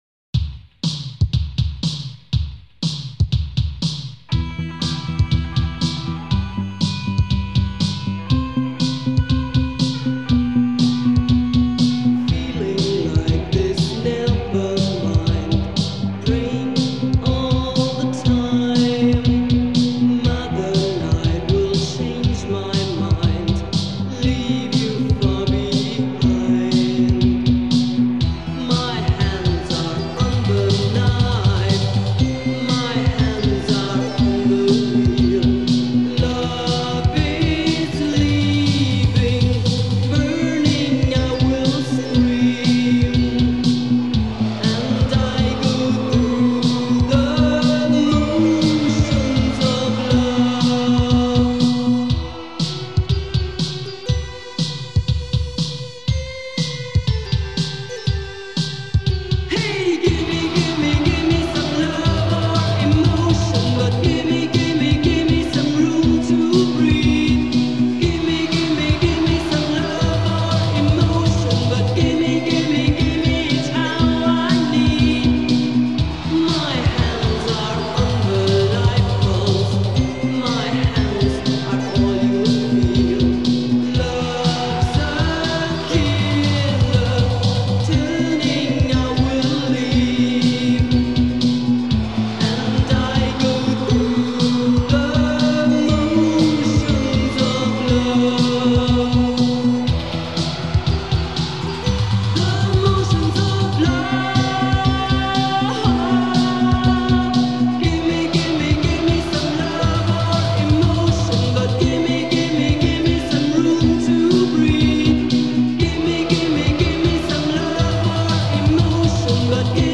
Electric Guitar, Electronic Drums [Drum Computer]
Vocals